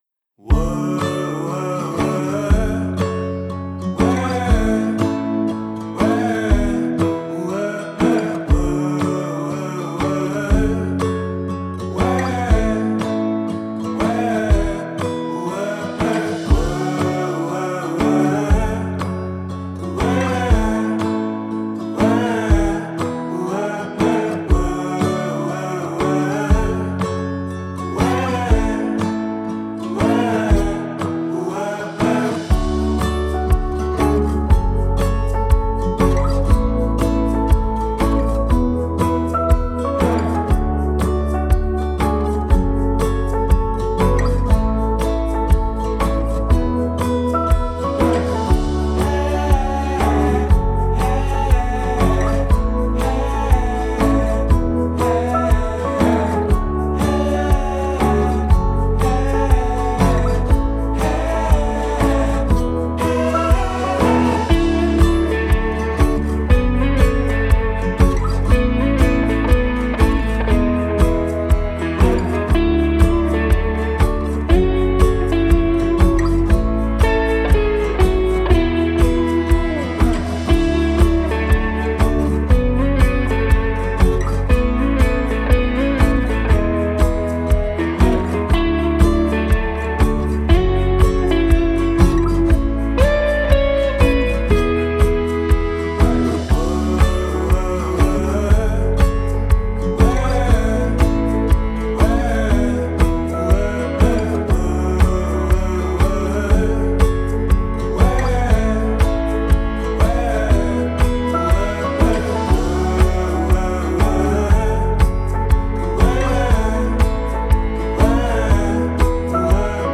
a sentimental guitar journey by a talented band.